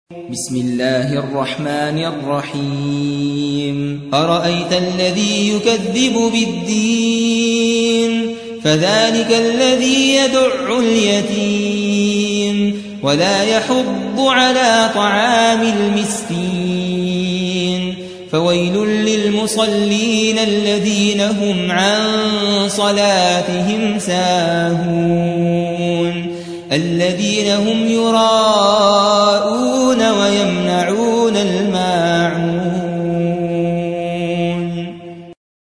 107. سورة الماعون / القارئ